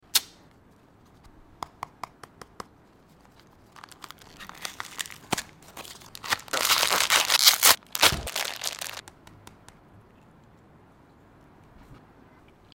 ASMR | Smiski Sunday Unboxing sound effects free download